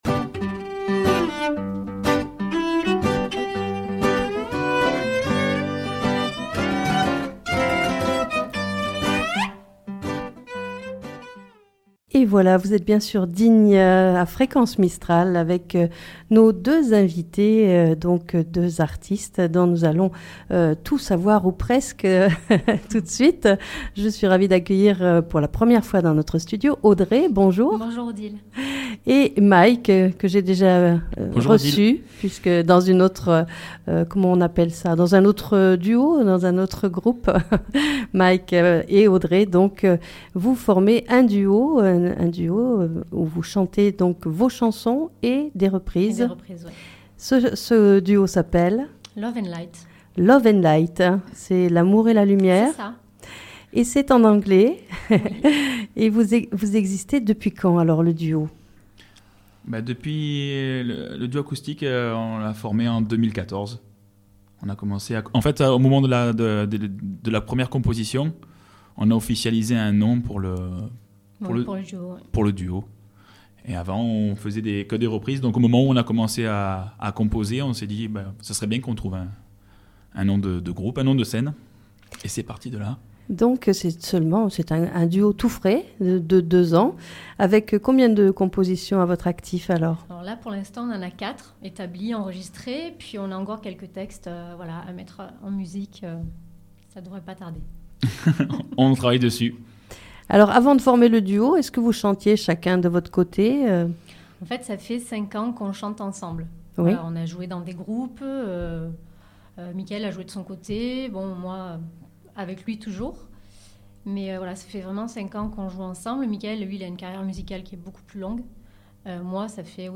Rencontre avec le duo Love and Light
Lui est guitariste mais aussi multi-instrumentiste. Elle chante. Il chante aussi, et leurs voix s'harmonisent parfaitement.